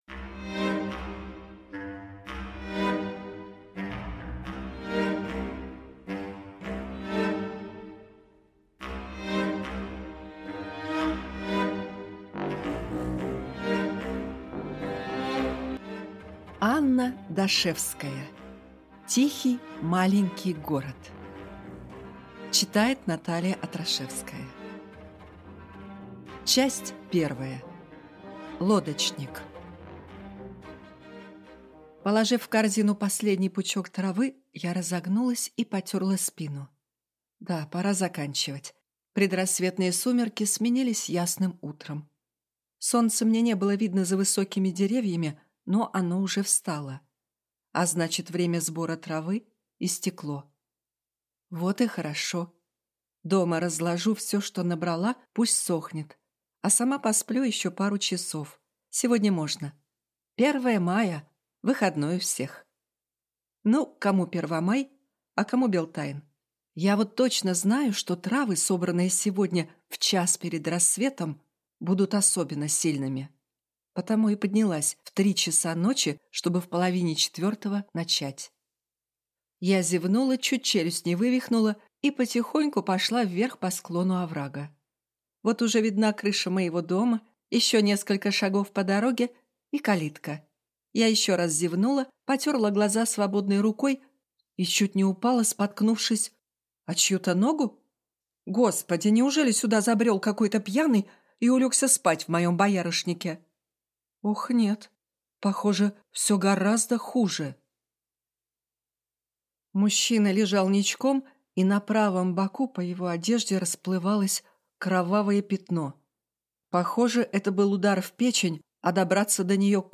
Аудиокнига Тихий маленький город | Библиотека аудиокниг